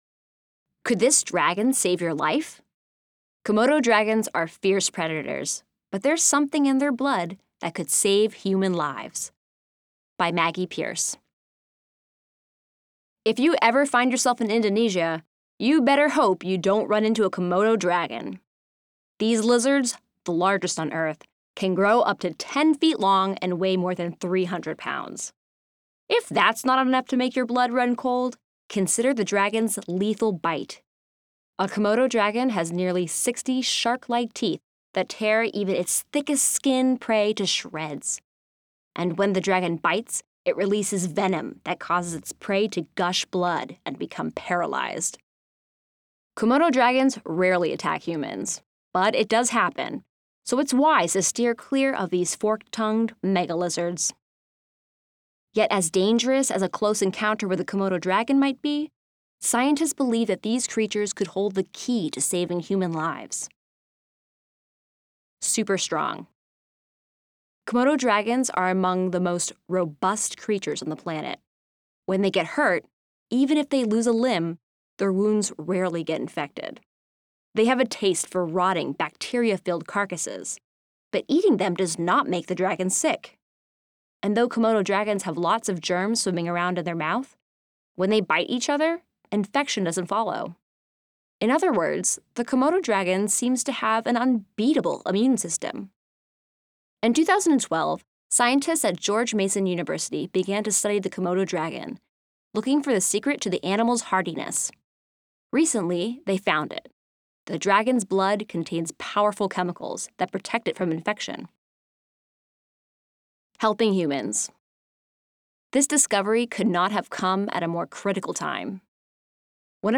audio version while students follow along in their printed issues.